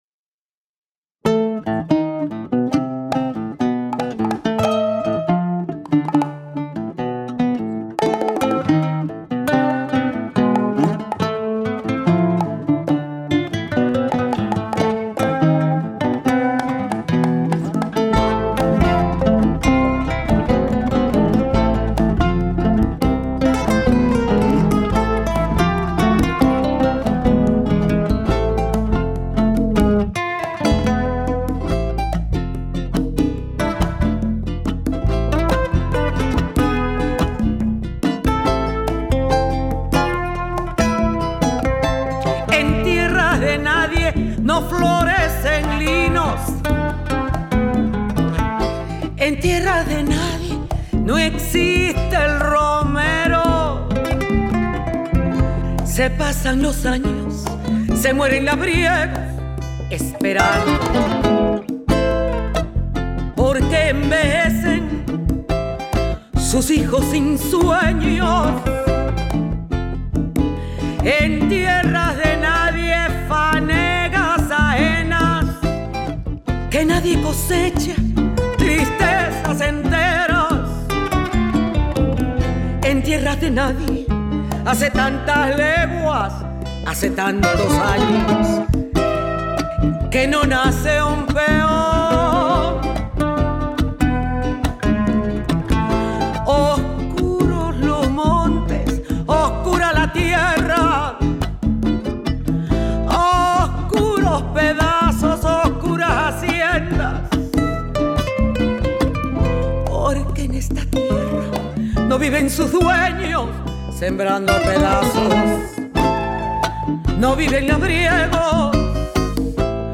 Declamación